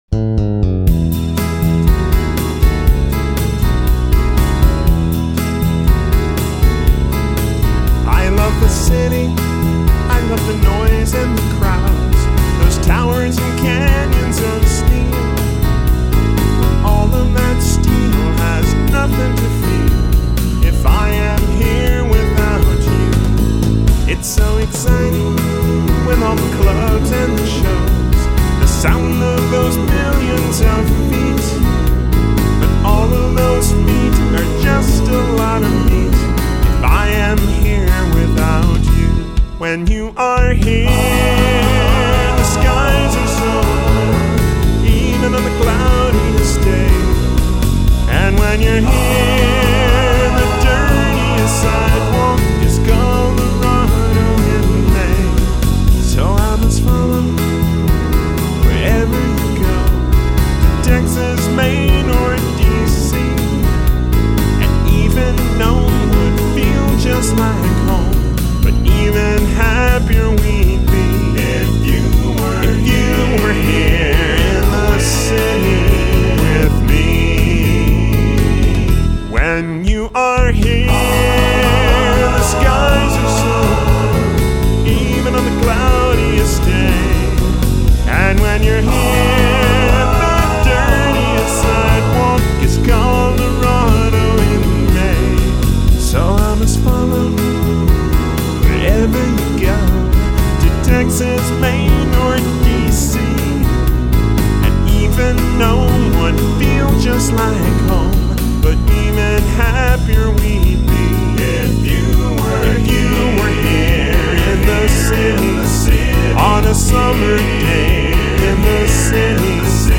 vocals / guitars / sequencing